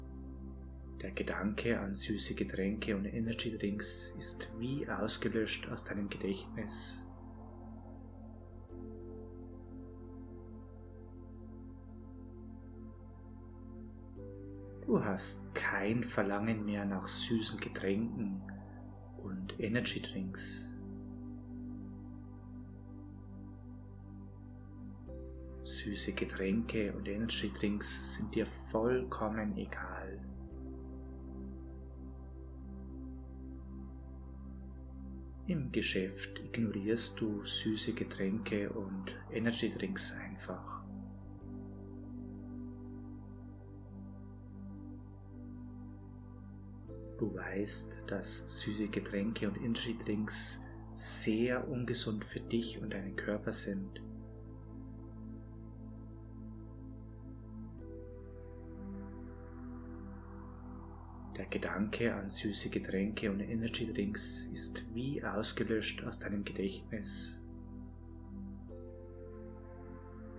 Mit Hilfe dieser geführten Hypnose können Sie leichter auf süße Getränke und Energy Drinks verzichten.
Genießen Sie während dieser MP3 eine wohltuende Entspannung und freuen Sie sich auf ein Leben ohne süße Getränke und Energy Drinks.